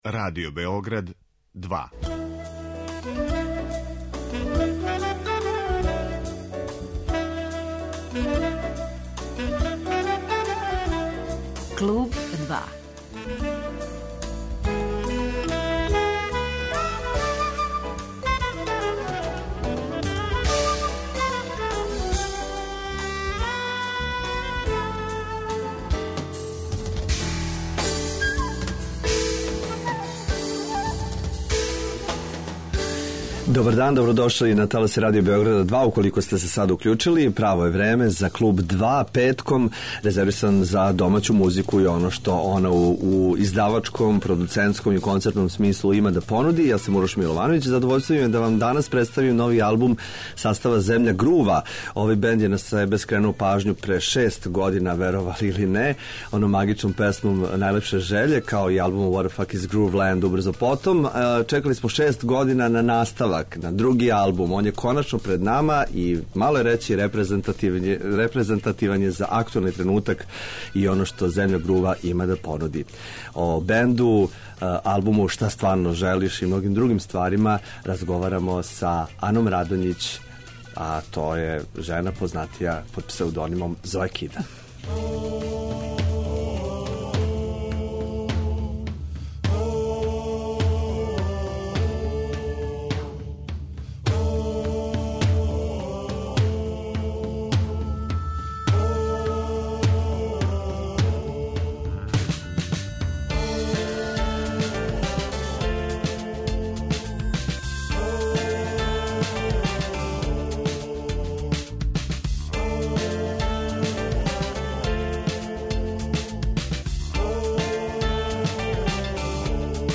Са друге стране, ово издање одише лакоћом и животном радошћу уз карактеристичан звук који из земље грува.